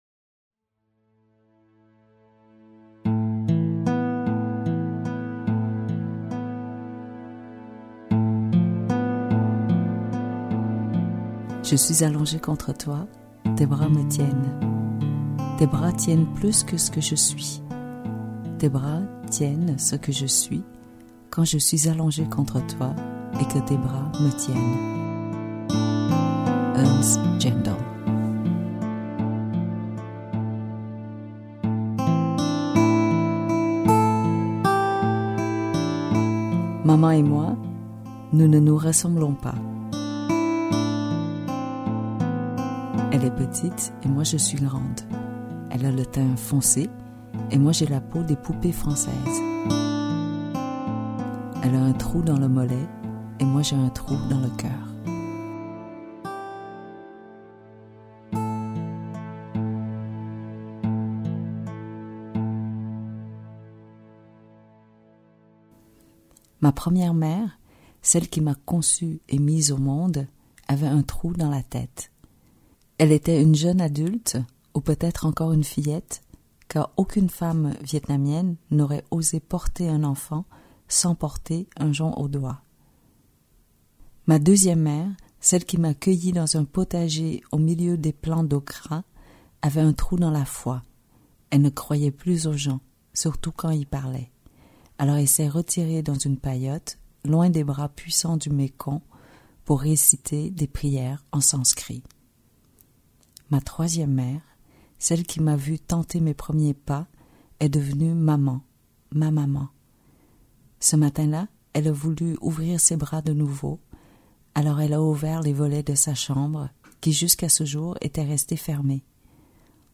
Click for an excerpt - Man de Kim THUY